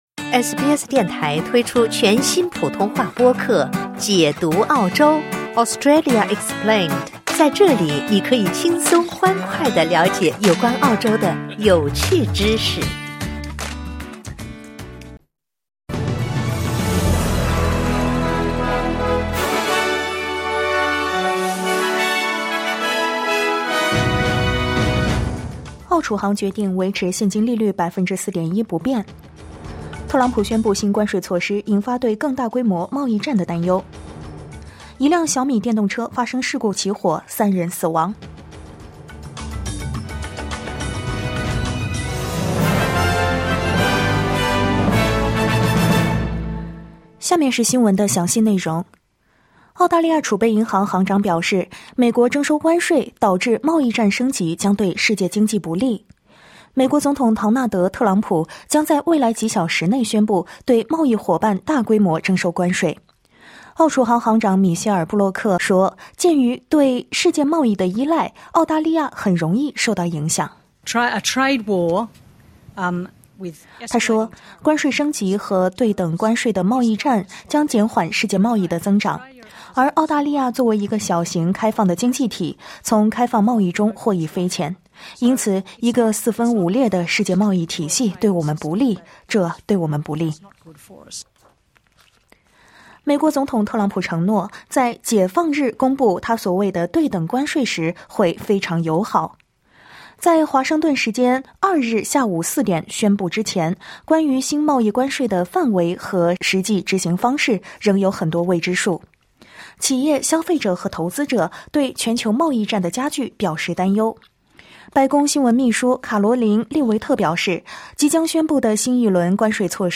SBS早新闻（2025年4月2日）